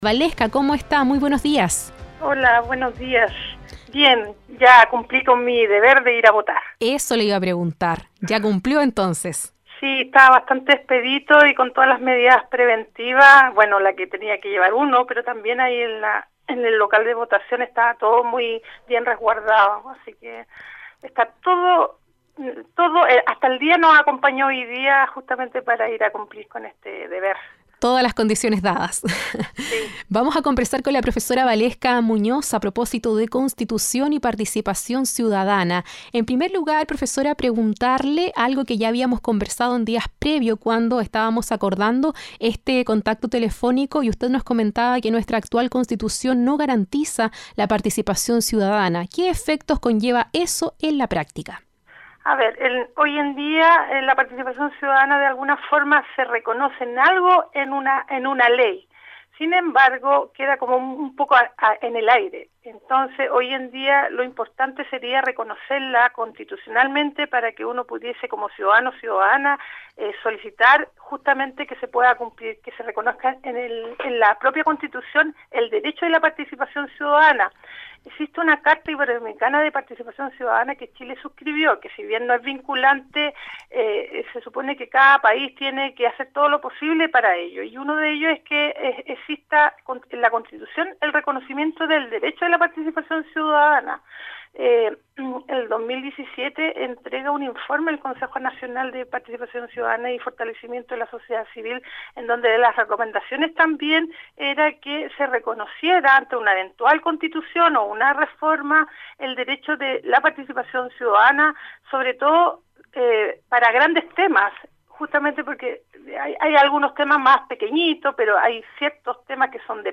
Sobre la investigación, explicó que existe un proyecto de recopilar los resultados en un libro que contará con el respaldo de la Facultad de Ciencias Jurídicas y Sociales UdeC. Revisa aquí la entrevista completa